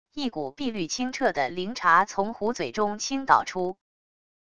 一股碧绿清澈的灵茶从壶嘴中倾倒出wav音频